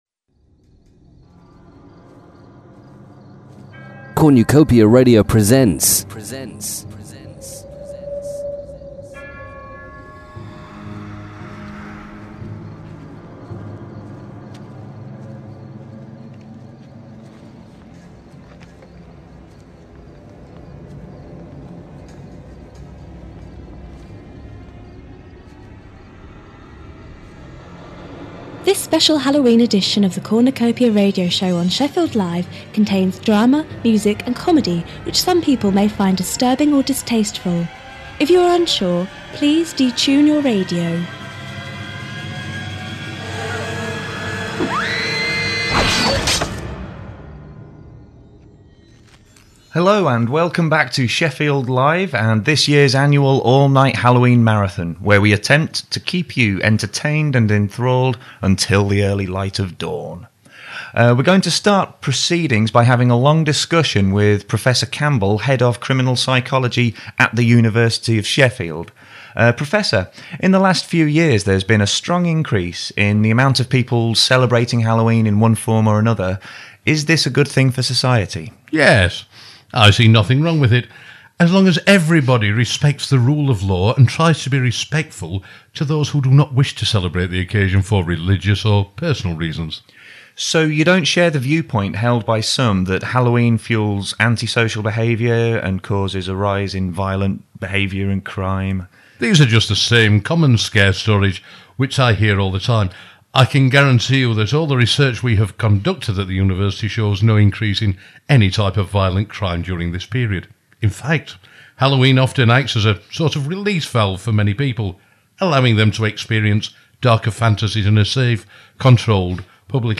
The Cornucopia Halloween Special, featuring brand new original short stories, plays, monologues, sketches and Halloween music.
Instead of giving you the full six hours; on this page you can listen to a special one hour production of all the best bits from the entire evening: (Warning: this programme might contain material some people could find offensive)